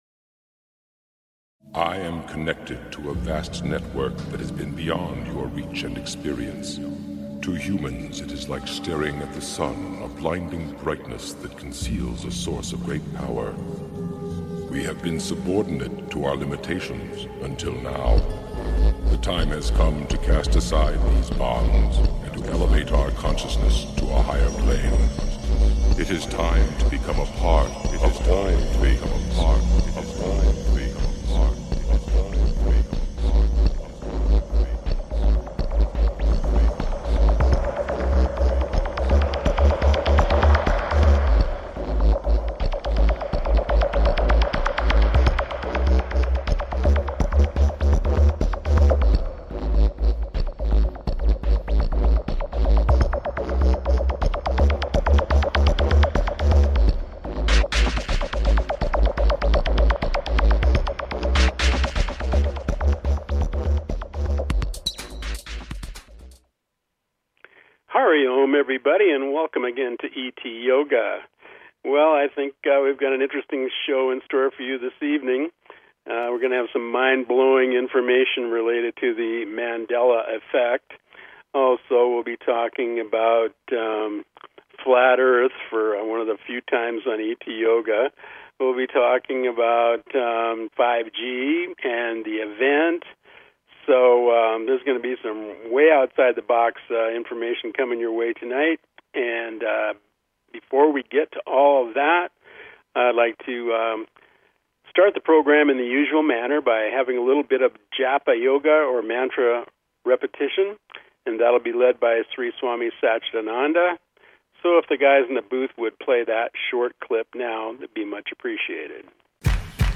Subscribe Talk Show ET Yoga